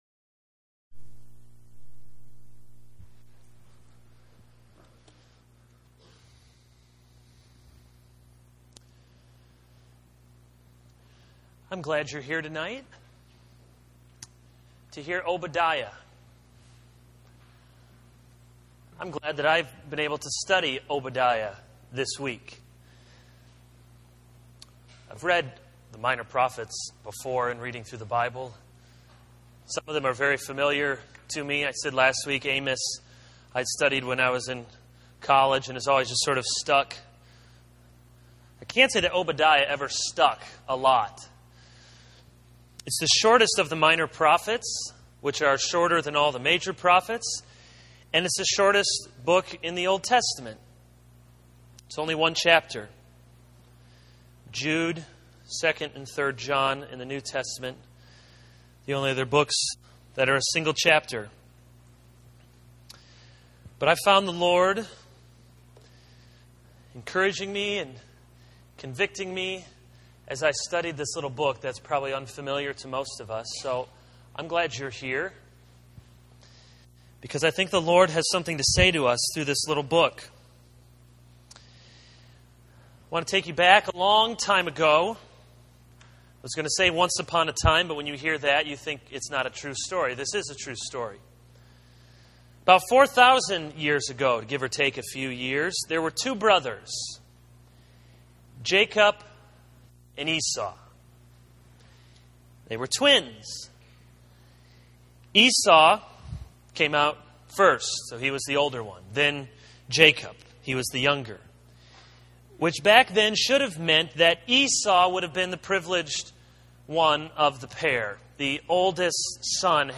This is a sermon on Obadiah 1:1-1:21.